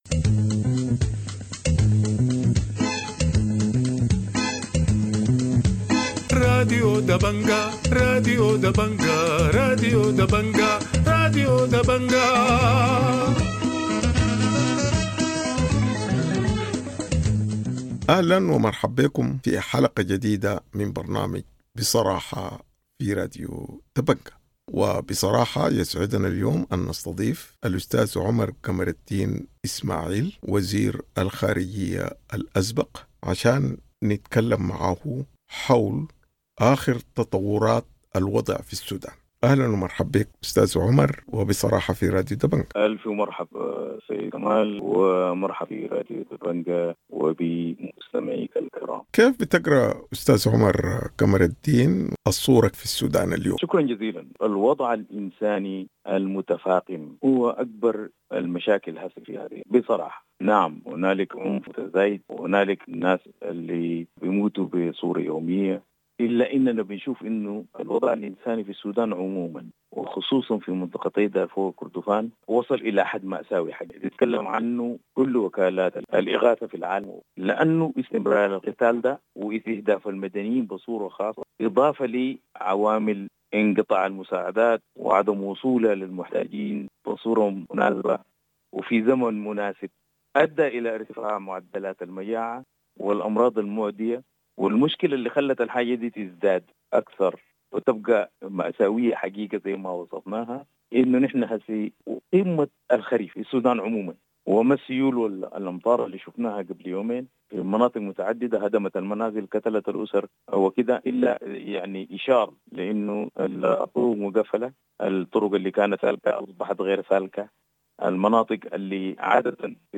In an exclusive interview